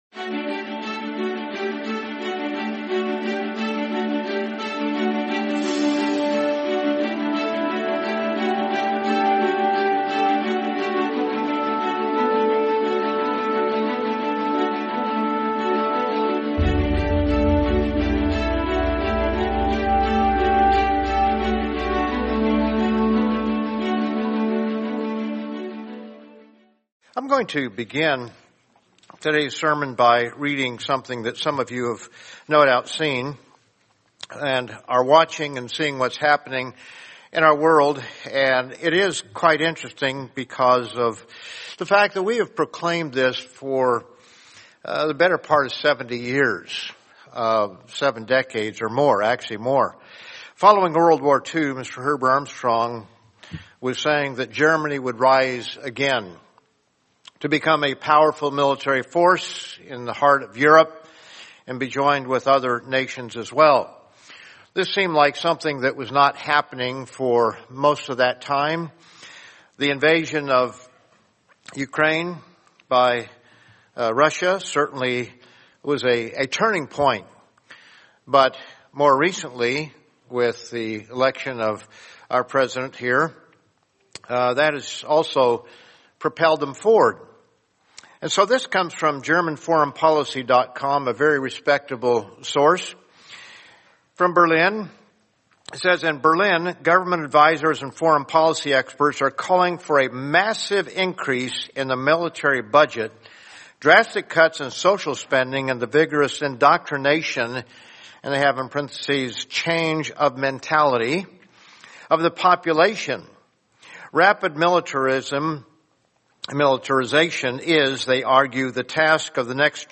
Sermon The Lamb of God and the Good Shepherd